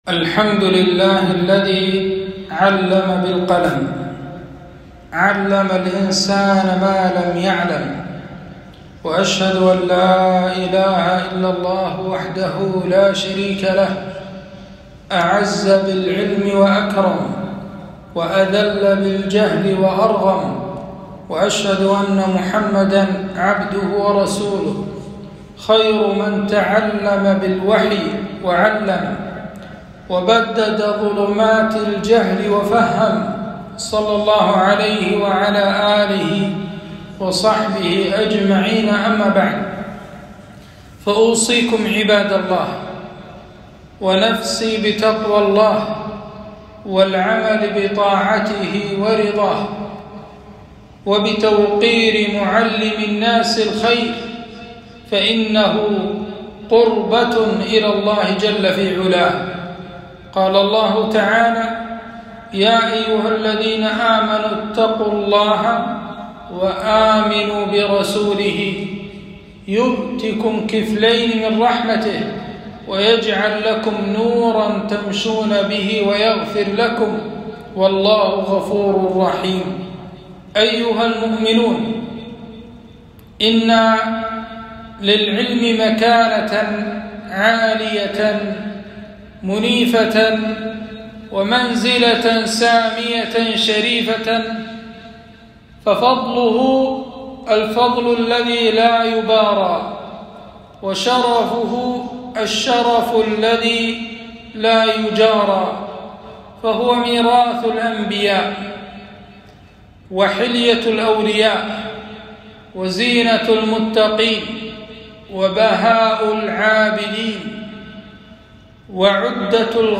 خطبة - العلم أهميته وفضل نشره